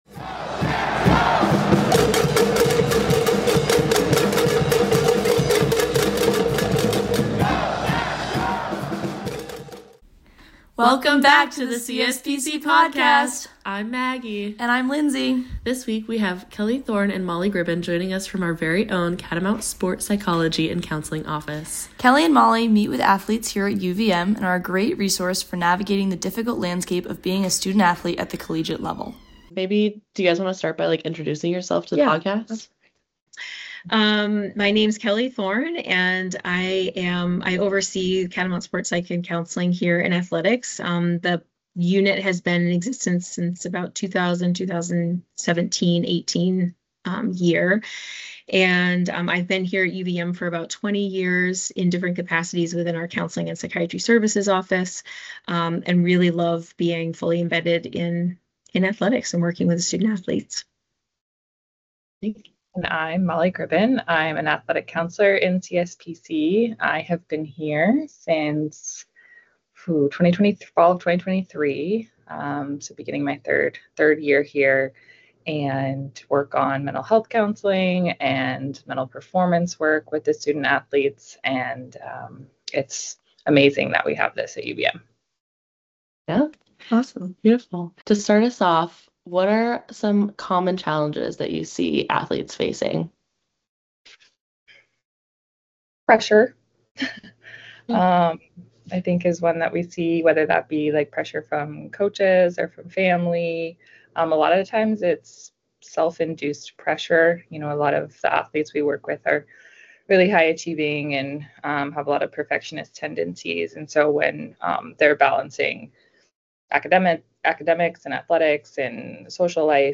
We interview